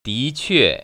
[díquè] 디취에